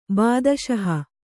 ♪ bādaśaha